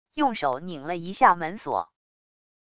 用手拧了一下门锁wav音频